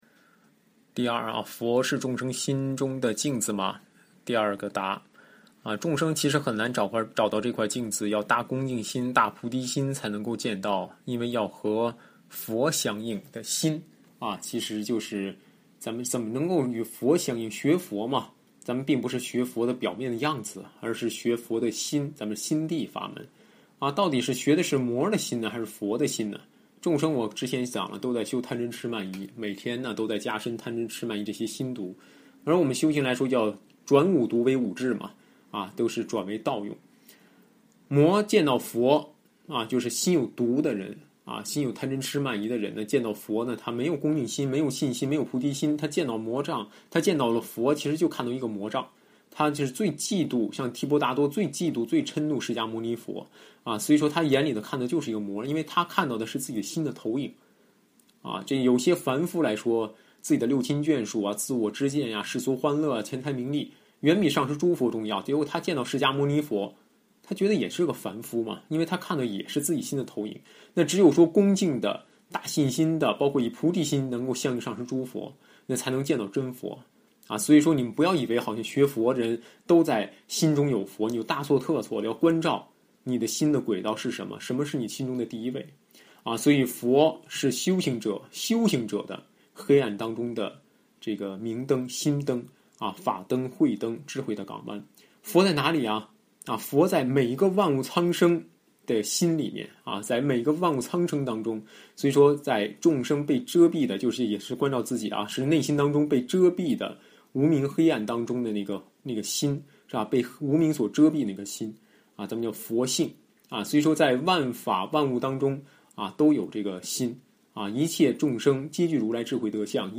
随笔开示
上师语音开示